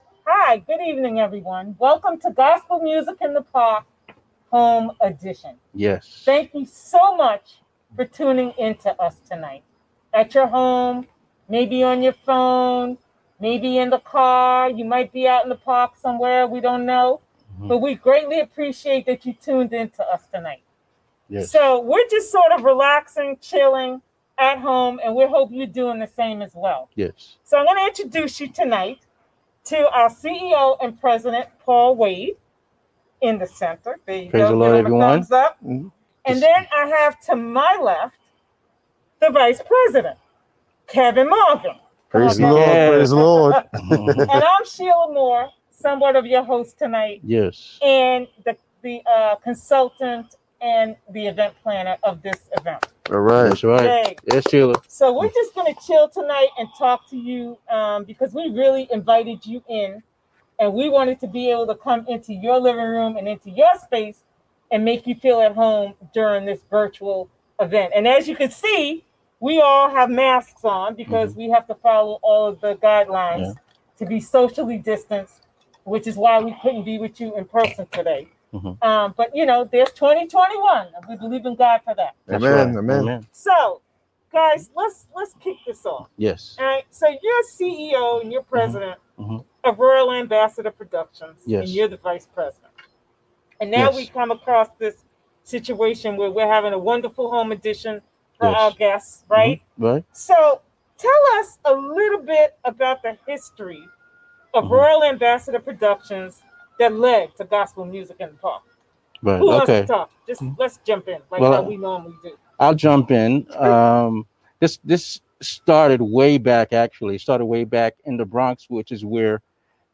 GMP-2020-Interview-clip-history.mp3